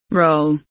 roll kelimesinin anlamı, resimli anlatımı ve sesli okunuşu